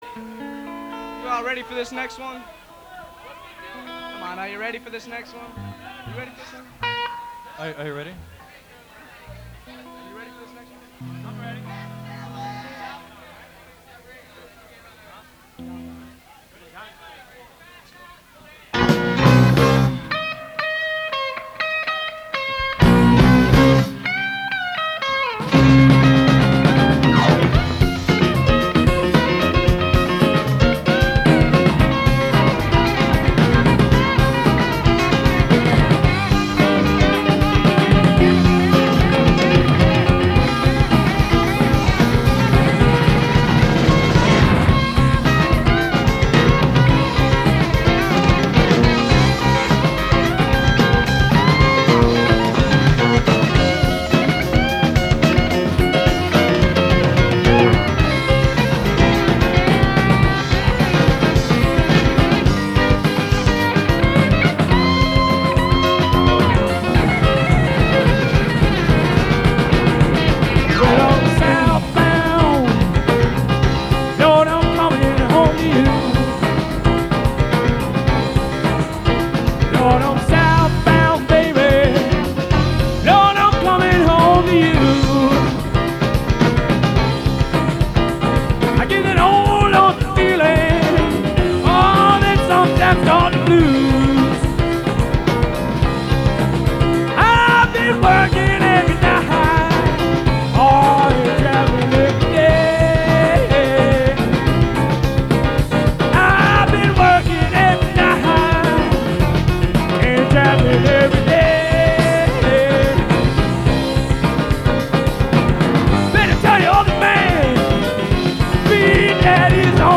Live Recordings